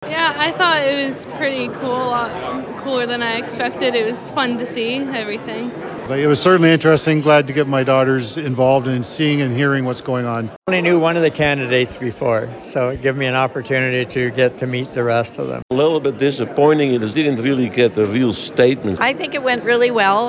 We also asked residents about their thoughts on the evening’s discussion.